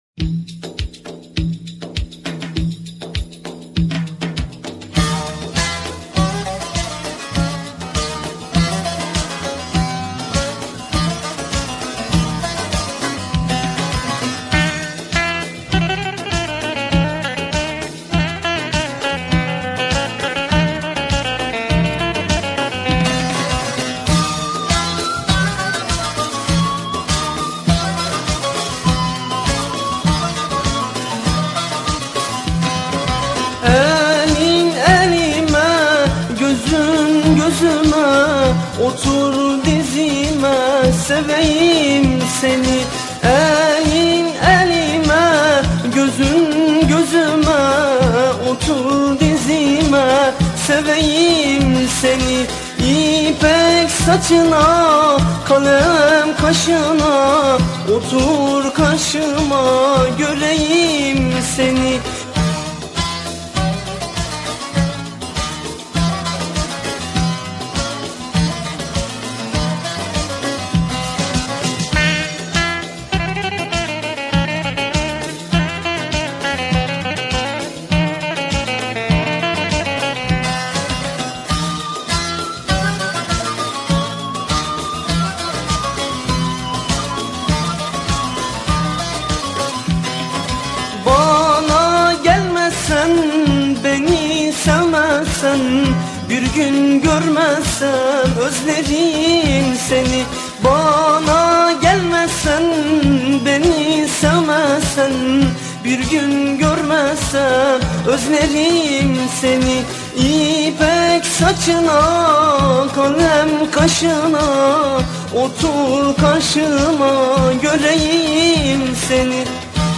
ژانر: پاپ ترکی